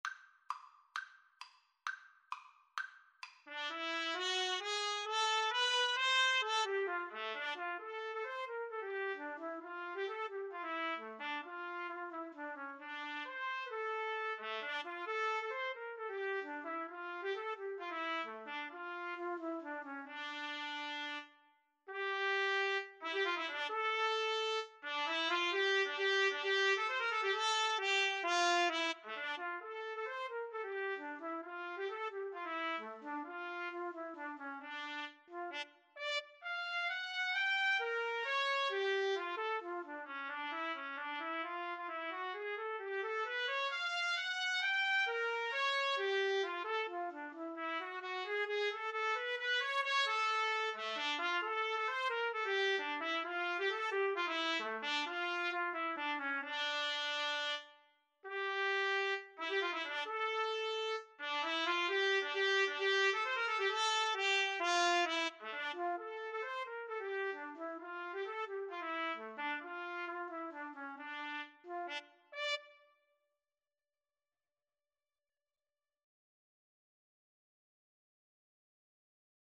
D minor (Sounding Pitch) E minor (Trumpet in Bb) (View more D minor Music for Trumpet Duet )
2/4 (View more 2/4 Music)
Traditional (View more Traditional Trumpet Duet Music)